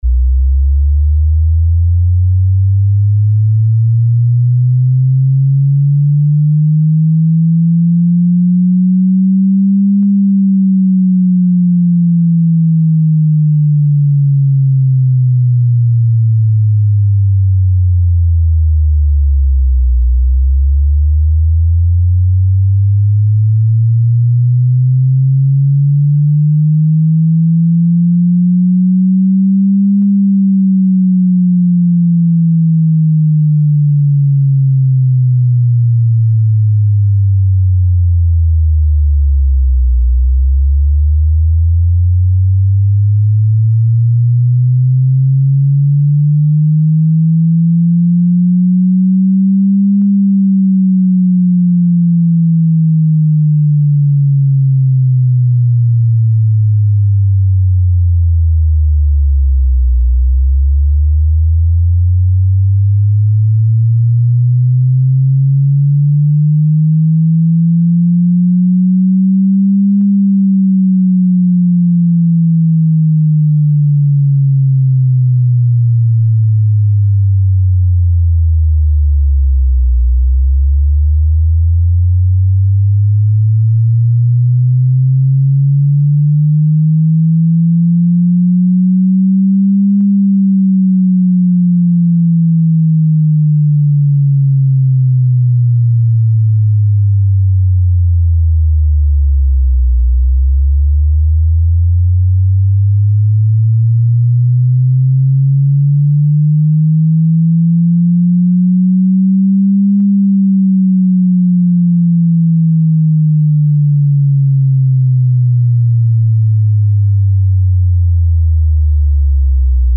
test drnčení, pusť to do toho a hned uslyšíš:-)